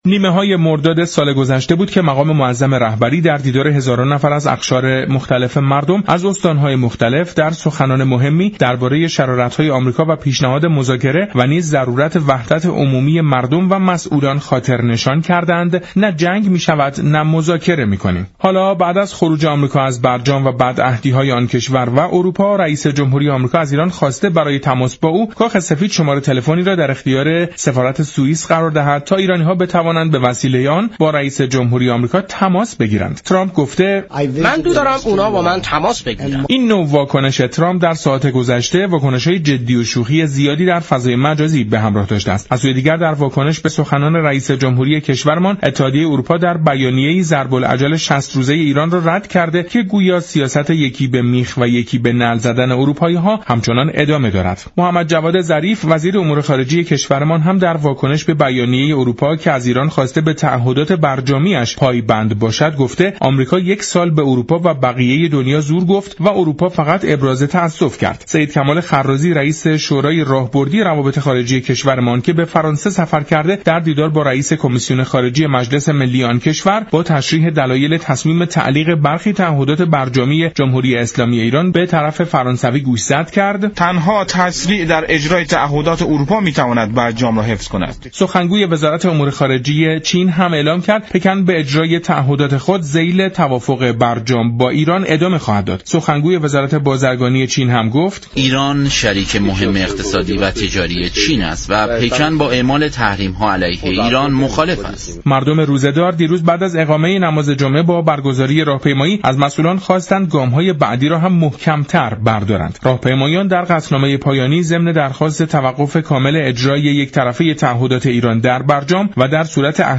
این گفت و گو را در ادامه باهم می شنویم.